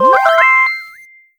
notificacion.ogg